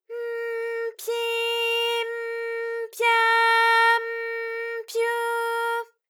ALYS-DB-001-JPN - First Japanese UTAU vocal library of ALYS.
py_m_pyi_m_pya_m_pyu.wav